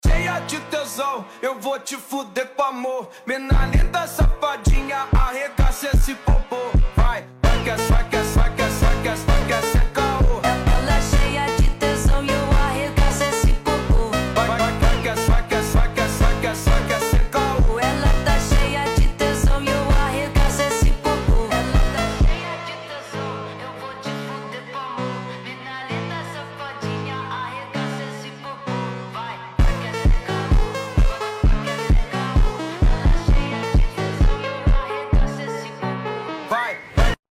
Brazilian celebration in football sound effects free download